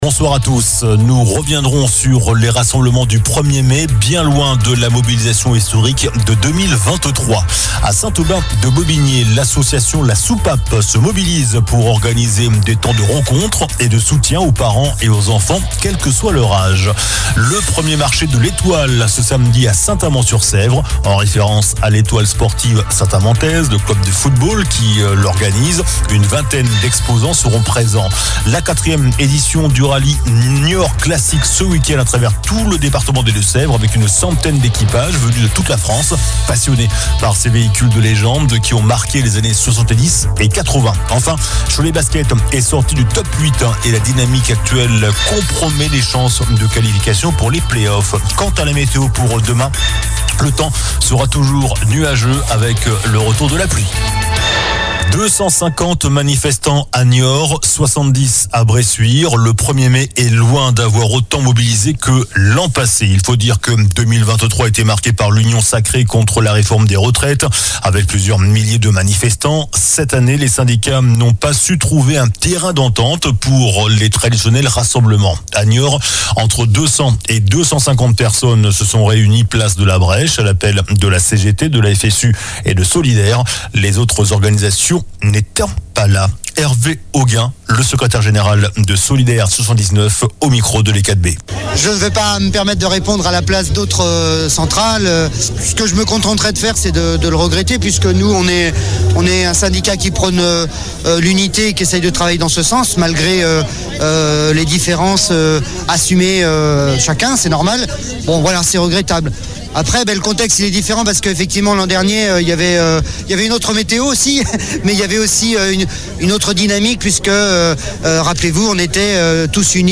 JOURNAL DU JEUDI 02 MAI ( SOIR )